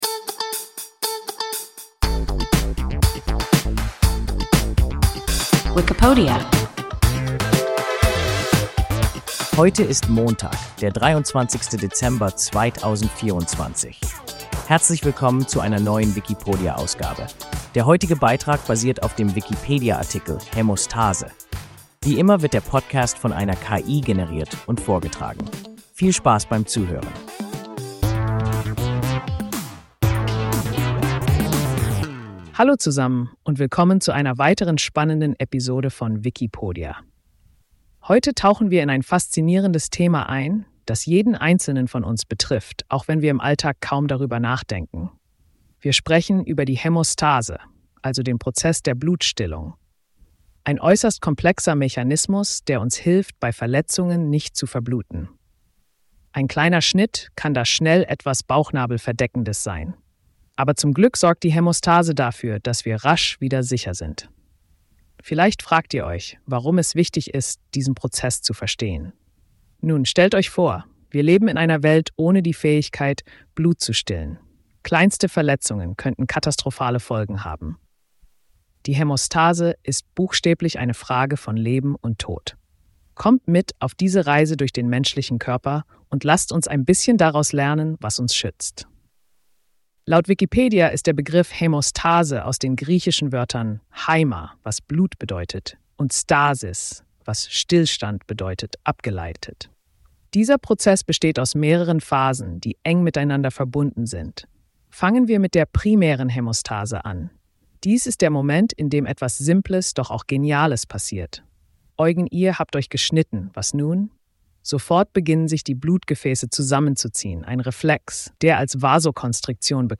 Hämostase – WIKIPODIA – ein KI Podcast